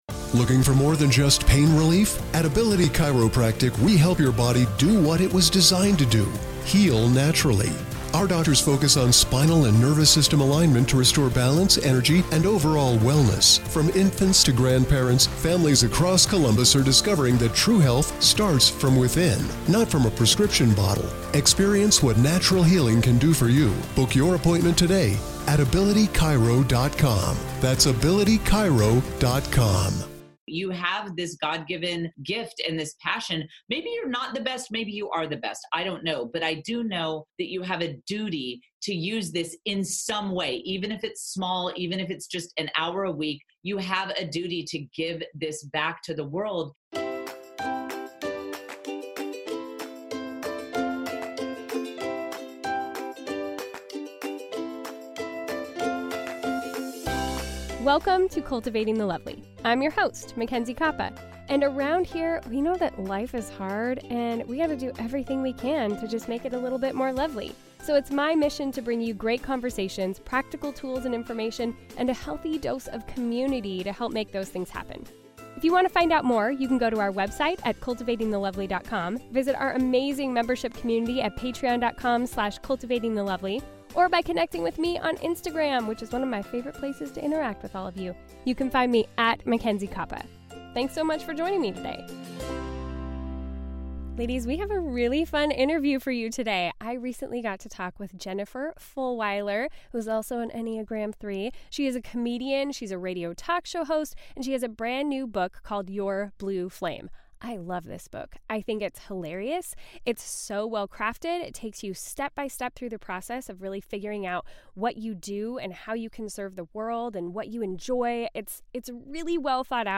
This is an encouraging and fun conversation, so sit back, relax and enjoy!